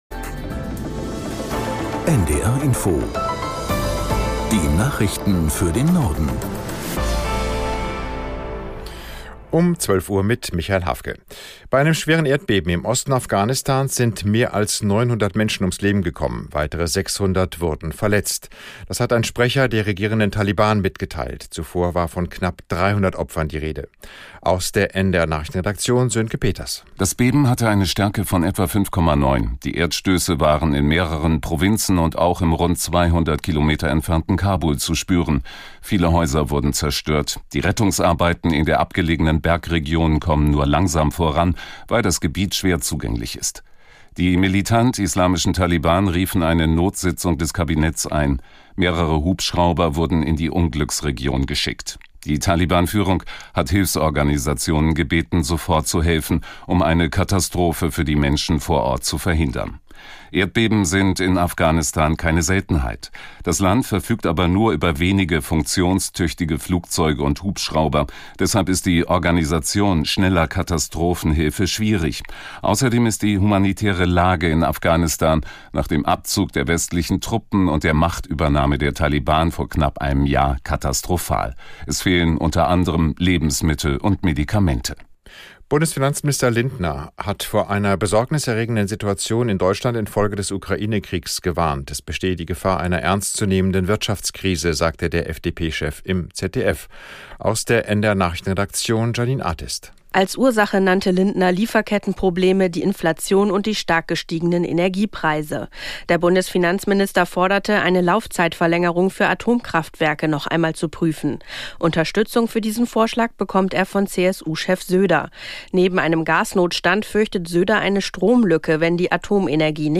Nachrichten - 15.07.2022